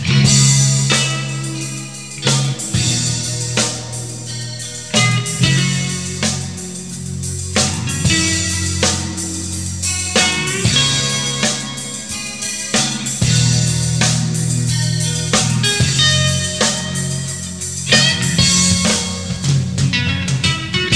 three piece band
vocals
drums